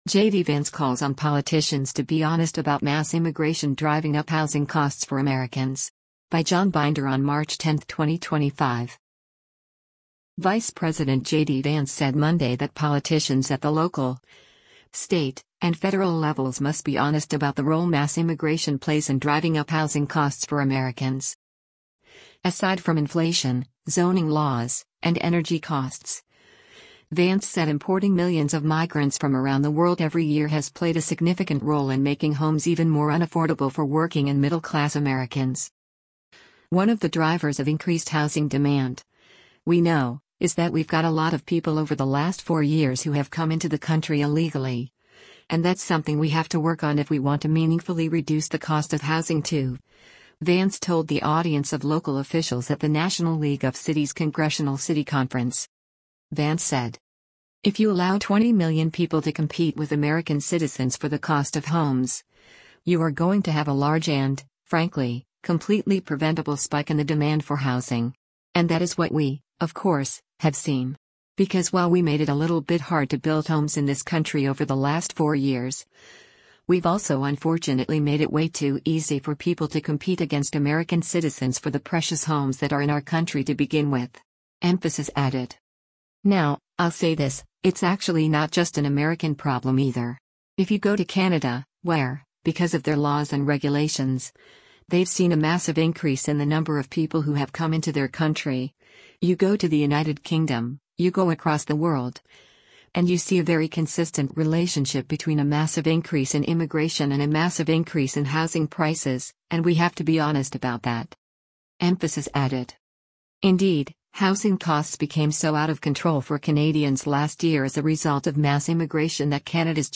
“One of the drivers of increased housing demand, we know, is that we’ve got a lot of people over the last four years who have come into the country illegally, and that’s something we have to work on if we want to meaningfully reduce the cost of housing too,” Vance told the audience of local officials at the National League of Cities’  Congressional City Conference.
At one point, a woman in the audience shouted at Vance as he was speaking, to which he responded, “I see one of our nice representatives out here wants to actually, I guess, continue to flood the country with illegal immigrants, making your communities and — and citizens unaffordable.”